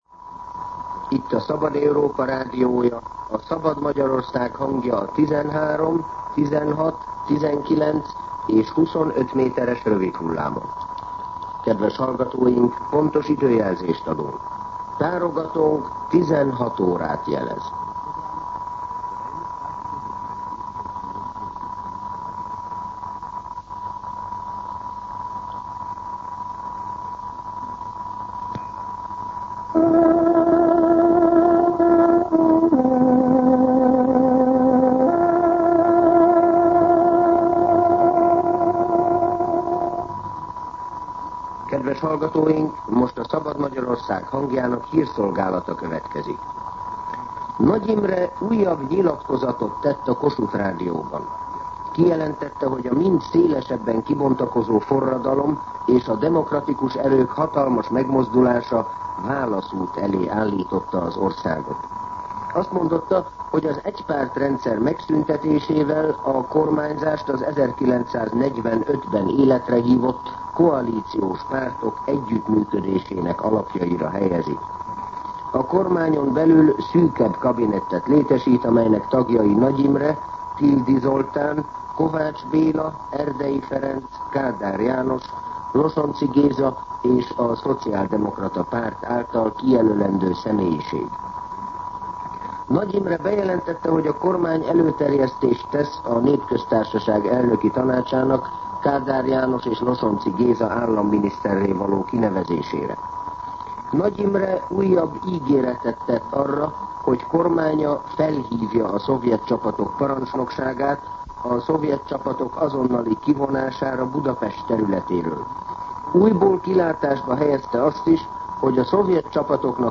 16:00 óra. Hírszolgálat